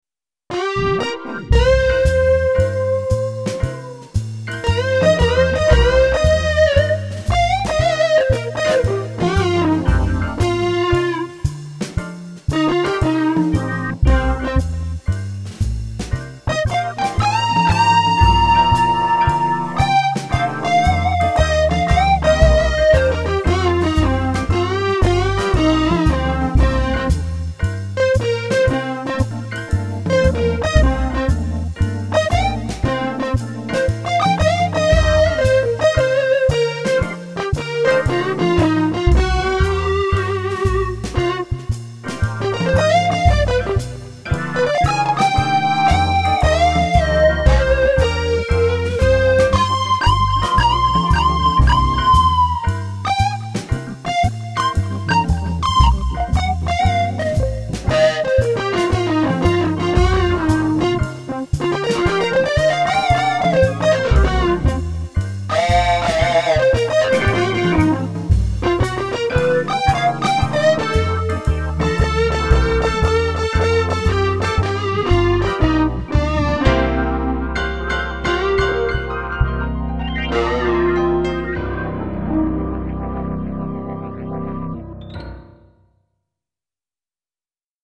These were all made using ManyGuitar: there has been no external processing (except a touch of compression/limiting).
ManyguitarBluesDemo.mp3